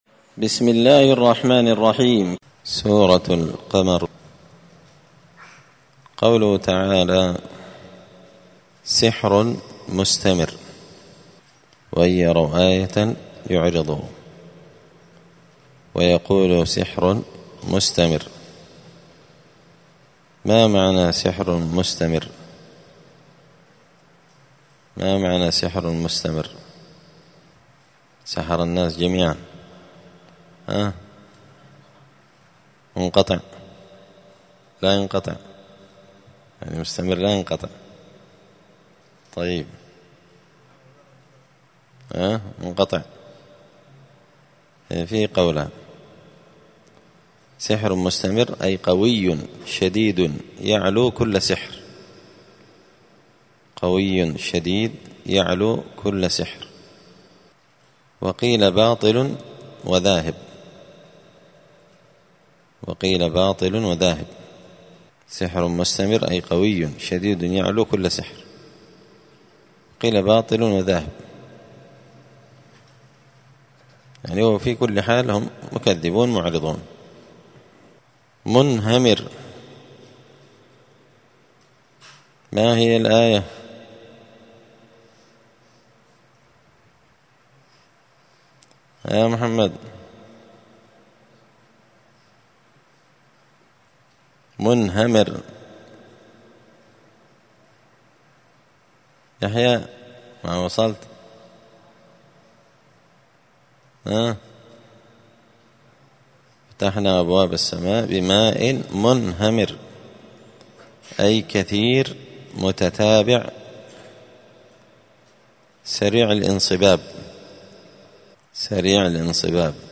دار الحديث السلفية بمسجد الفرقان بقشن المهرة اليمن
*المجالس الرمضانية لفهم معاني السور القرآنية*